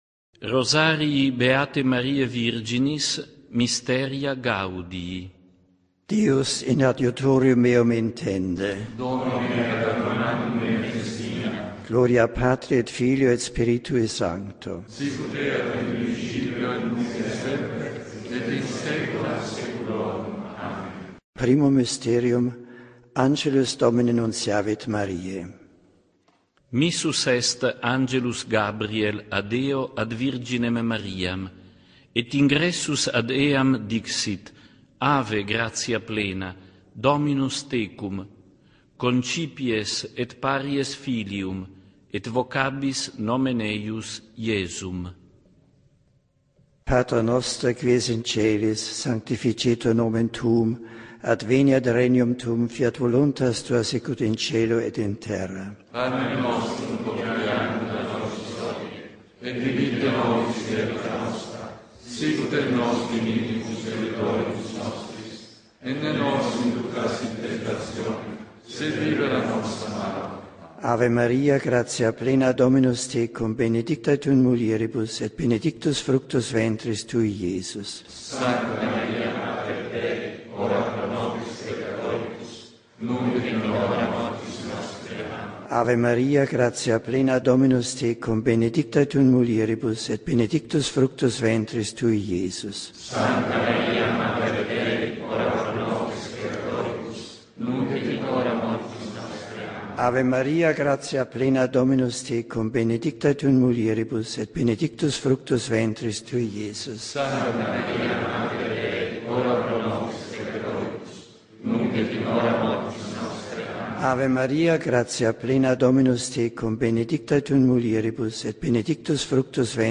Mysteria Gaudii (audio) Trascription of the Joyful Mysteries Rosary in Latin as recited by Pope Emeritus Benedict XVI